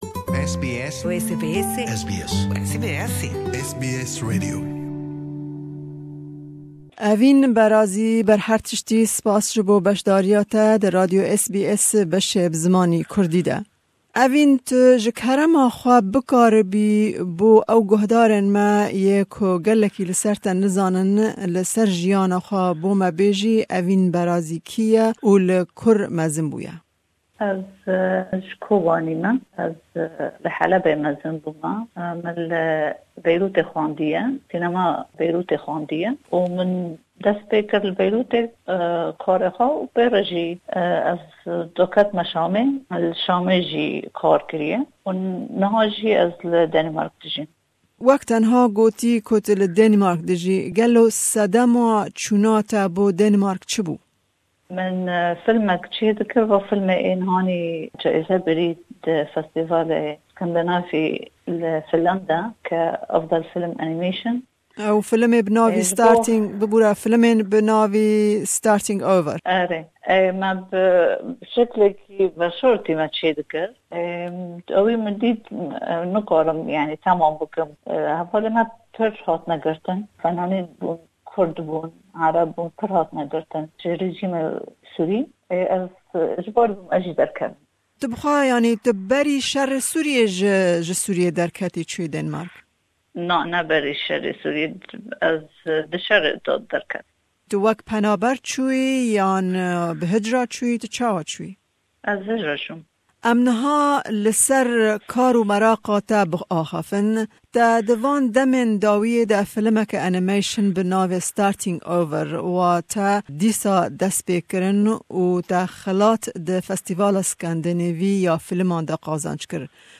Me hevpeyvînek